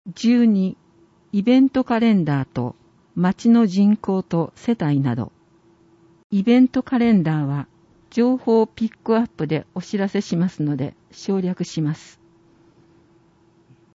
広報とうごう音訳版（2021年8月号）